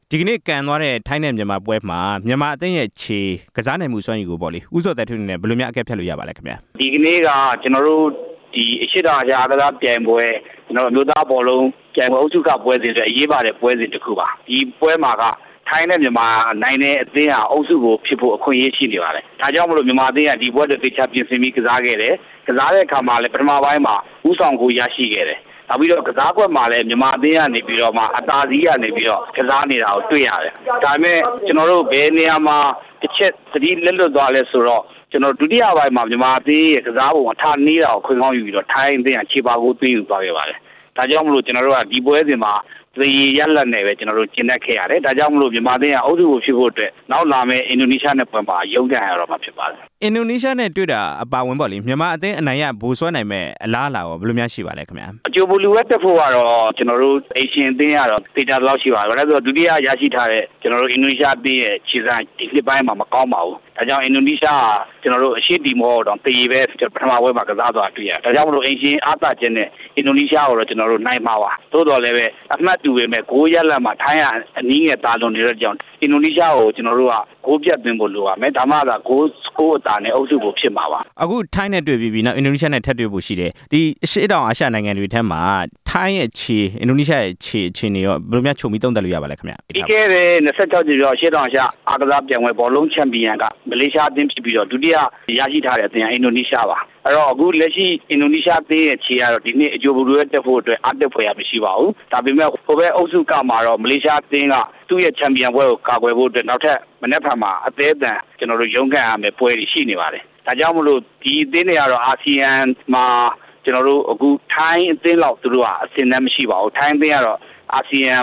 မြန်မာဘောလုံးအသင်း ဗိုလ်စွဲရေး အလားအလာ ဆက်သွယ်မေးမြန်းချက်